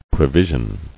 [pre·vi·sion]